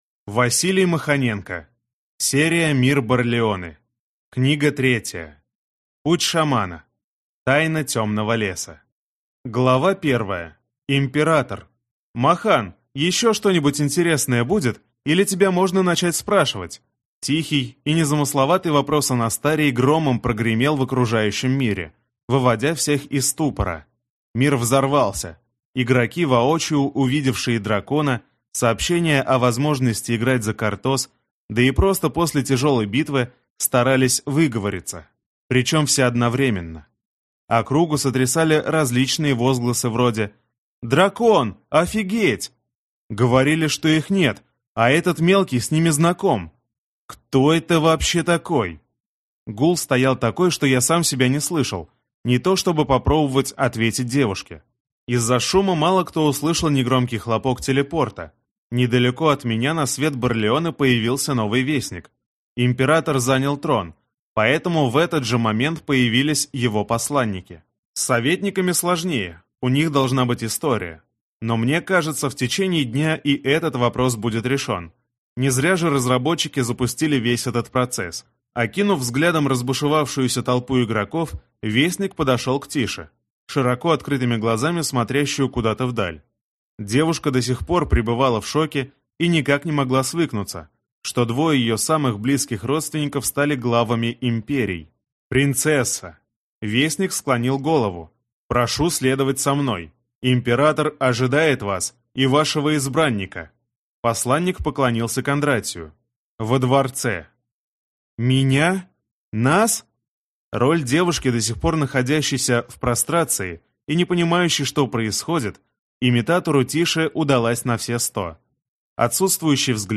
Аудиокнига Путь Шамана. Тайна Темного леса | Библиотека аудиокниг